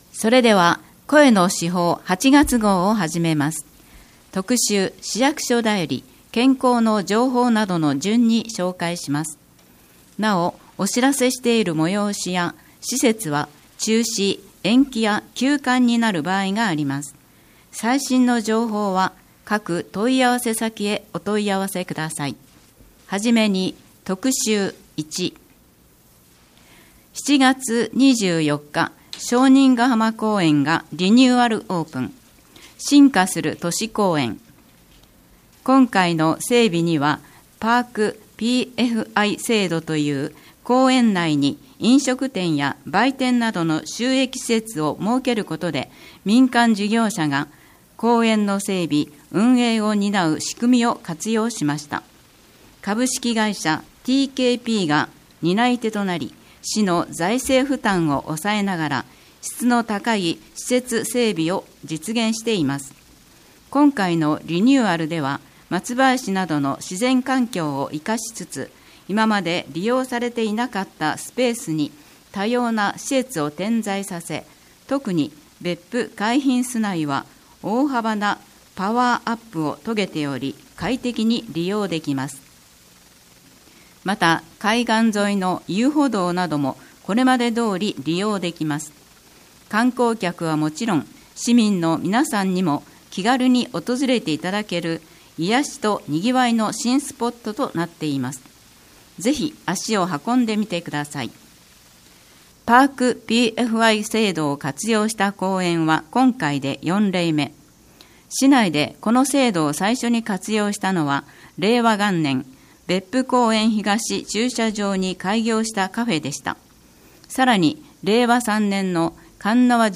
毎月市報べっぷの中から、視覚に障がいがある皆さんに特にお知らせしたい記事などを取り上げ、ボランティアグループ「わたげの会」の皆さんに朗読していただいて作られています。